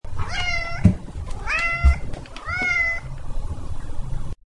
Nagging Cat Bouton sonore